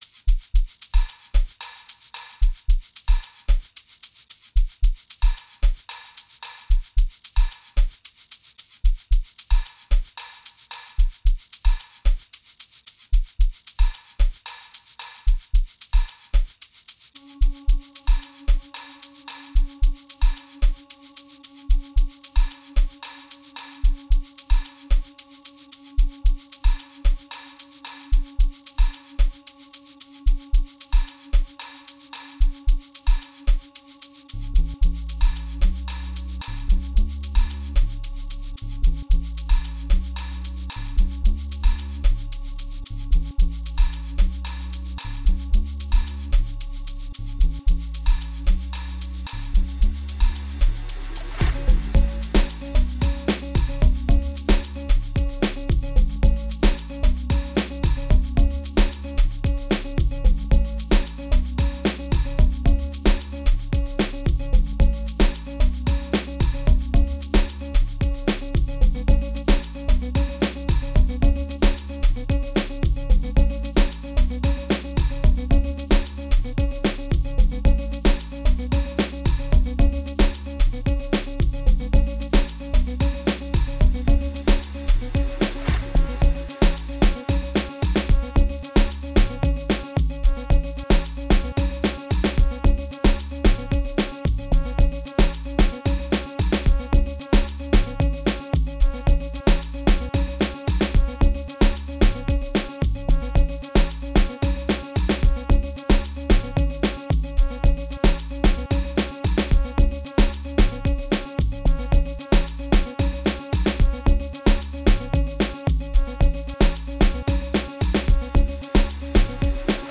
04:42 Genre : Amapiano Size